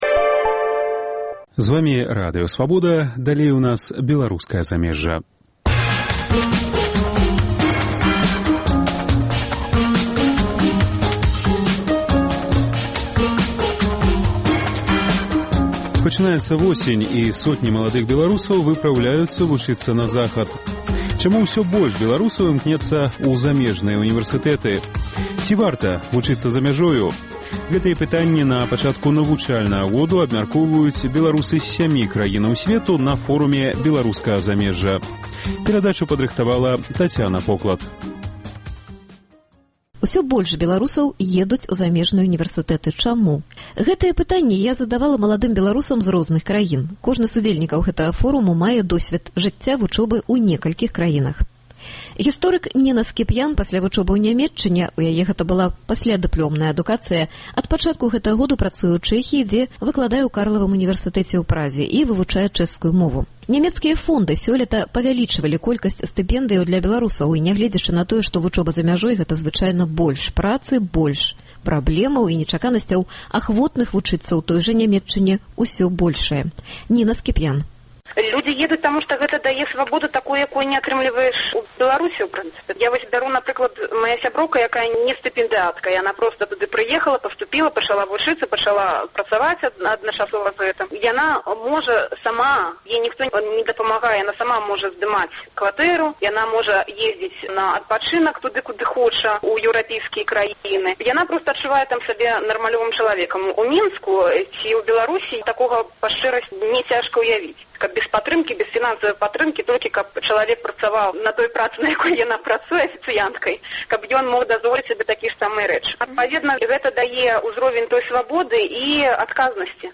Ці варта вучыцца за мяжой – гэтае пытаньне напачатку навучальнага году абмяркоўваюць беларусы з сямі краін сьвету на Форуме Беларускага замежжа.